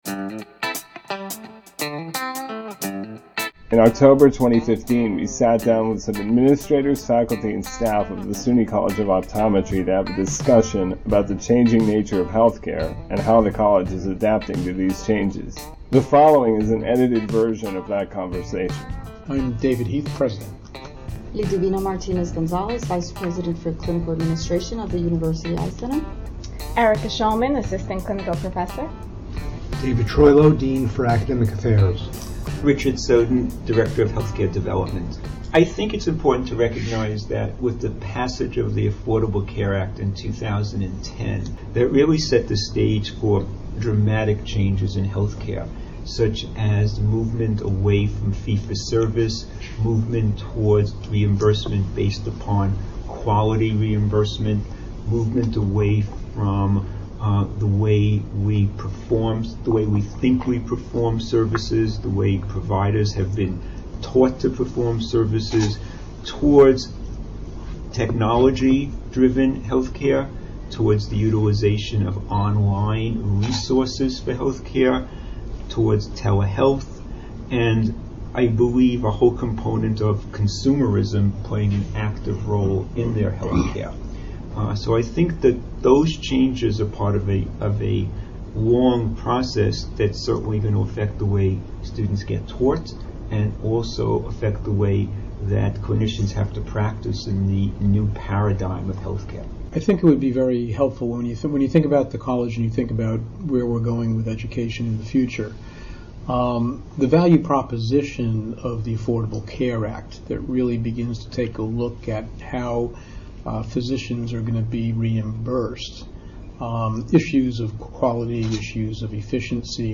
That discussion was edited into a podcast which can be listened to below